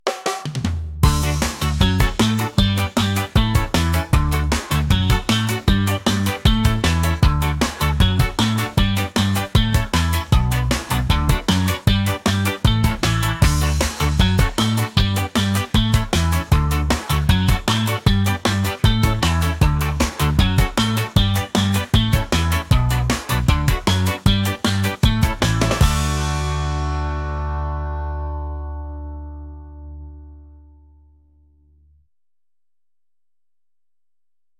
catchy | reggae | upbeat